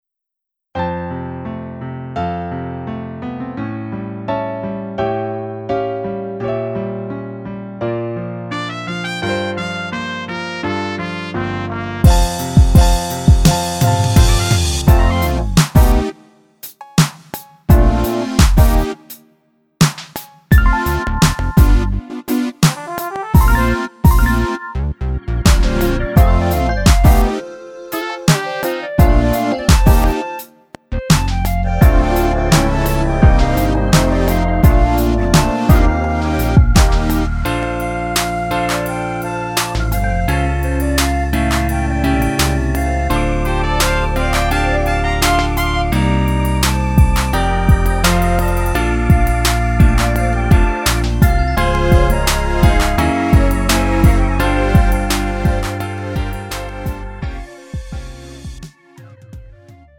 미리듣기
음정 -1키
장르 가요 구분 Lite MR